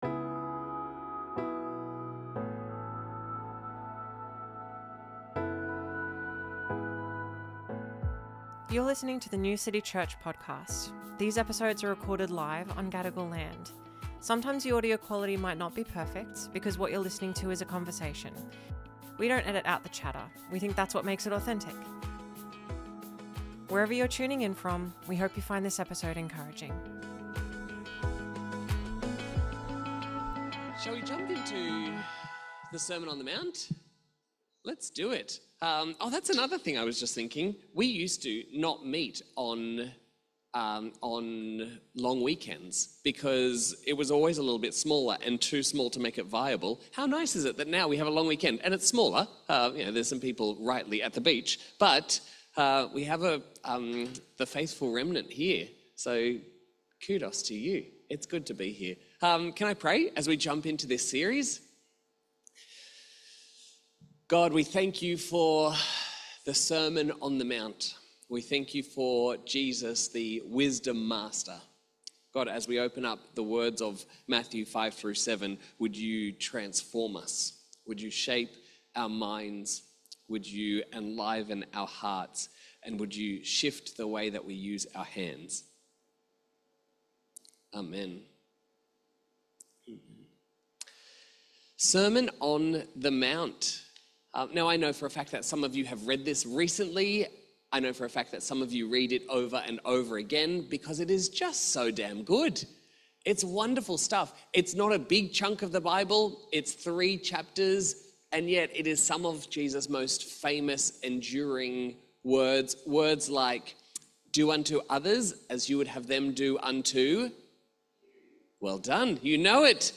Sermons | New City Church